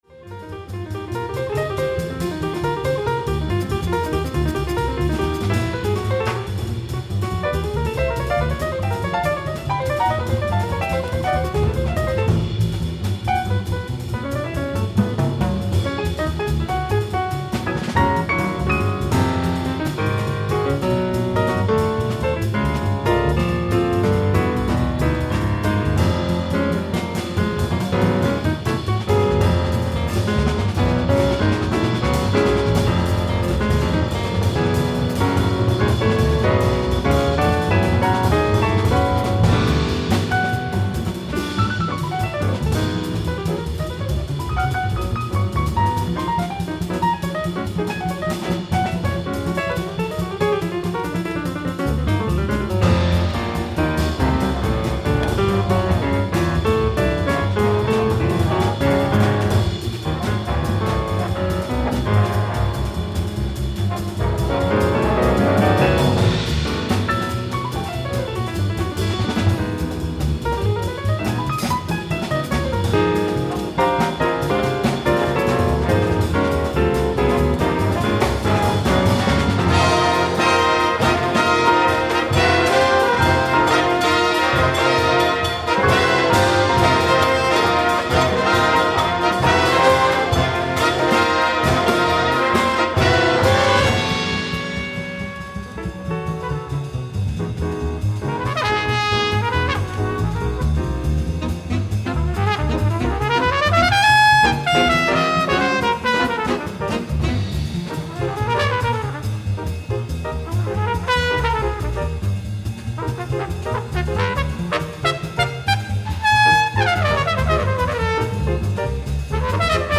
ライブ・アット・ローズ・シアター、リンカーンセンター、ニューヨーク 05/17+18/2013
※試聴用に実際より音質を落としています。